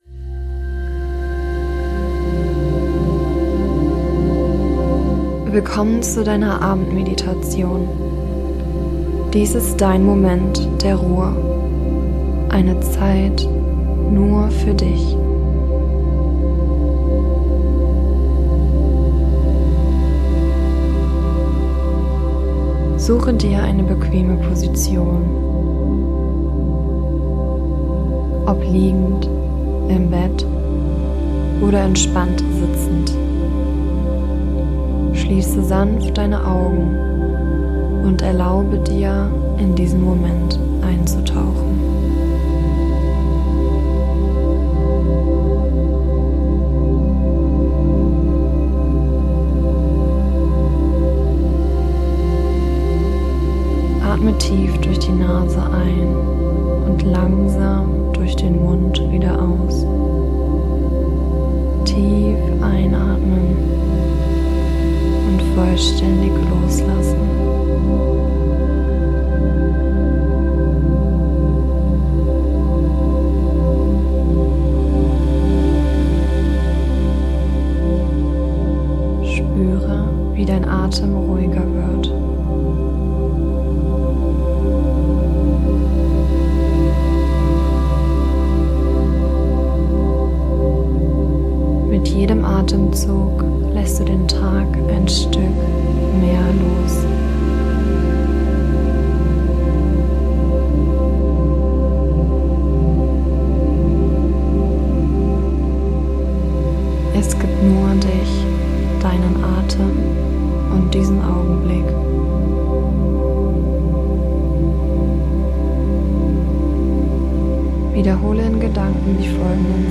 #003 8 Minuten Einschlafmeditation - Perfekte Vorbereitung für deinen 5 AM Club